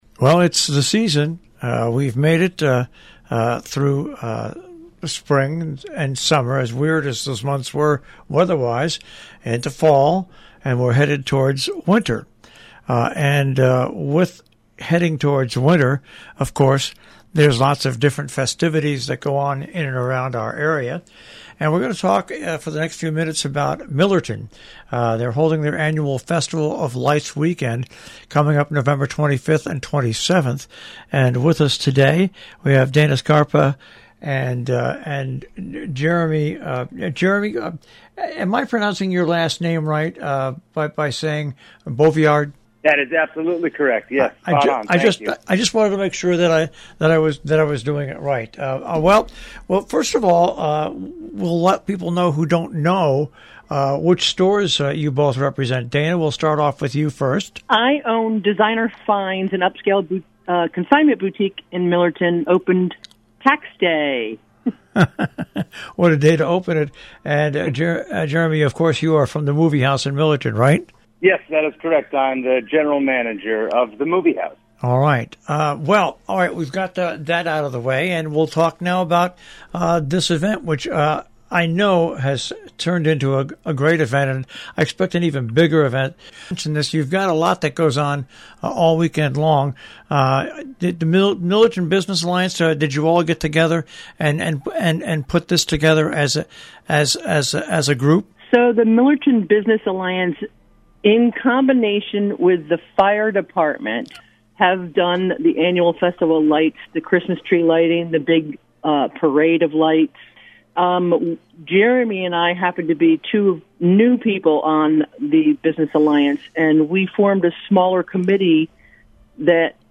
ROBIN HOOD RADIO INTERVIEWS